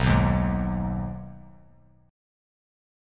B6 Chord
Listen to B6 strummed